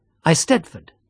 eisteddfod [əiˈstɛðvɔd]